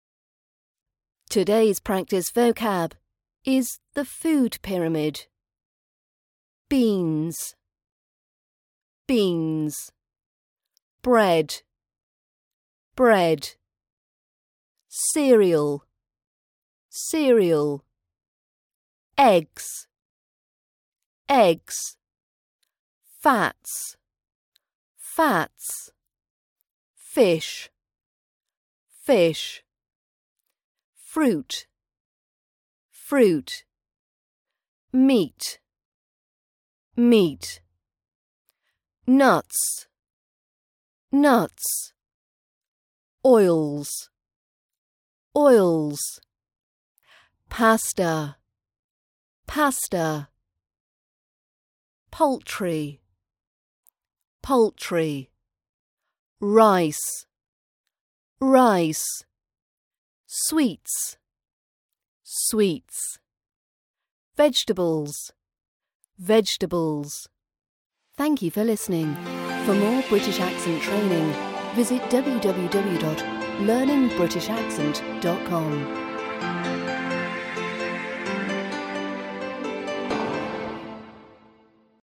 RP British Accent Vocab practice - The Food Pyramid